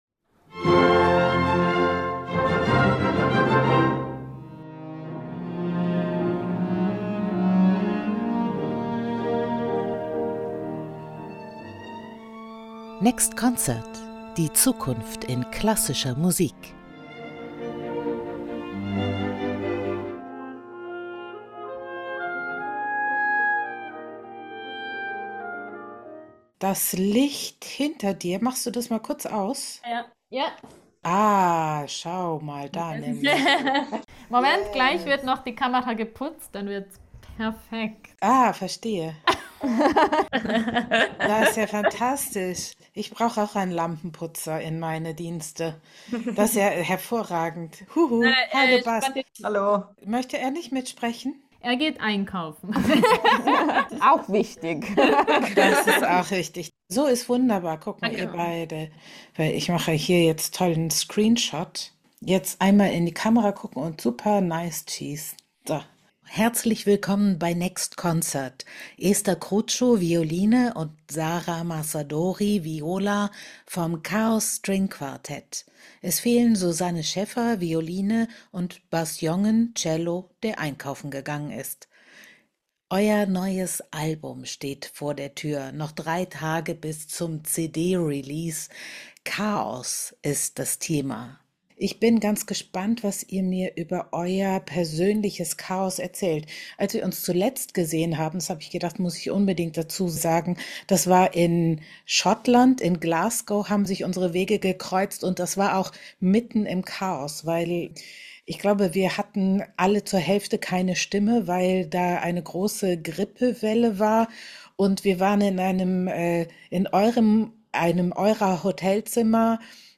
Das Streichquartett erklärt musikalisch seinen Namen. Von Rameau über Bach bis Ligeti, mit genialen Transitions von Samu Gryllus.